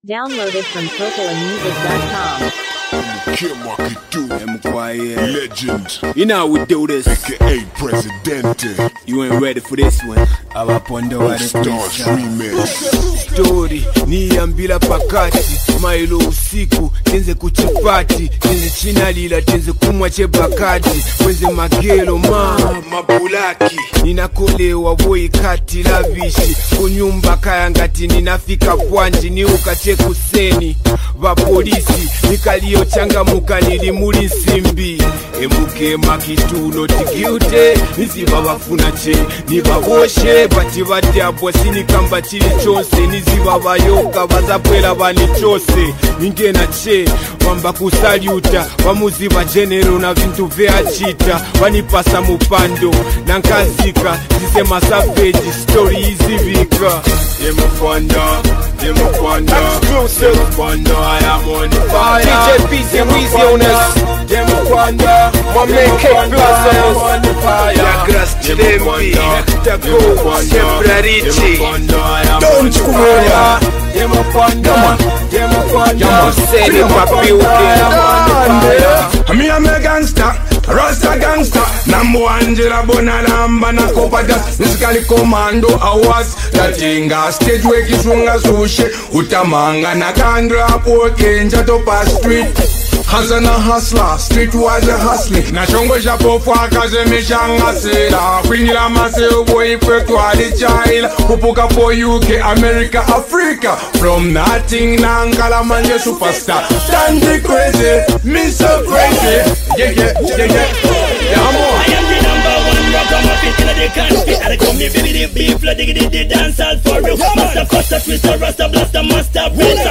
powerful and celebratory anthem